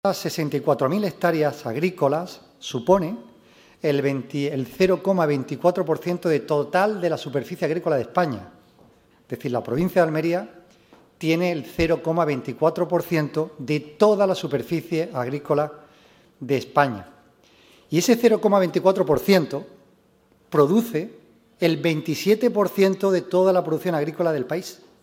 Esta cita que se ha celebrado en el Teatro Apolo de Almería ha sido inaugurada por la alcaldesa de Almería, María del Mar Vázquez, el presidente de Diputación, Javier A. García, así como por el consejero de Agricultura, Pesca, Agua y Desarrollo Rural, Ramón Fernández-Pacheco.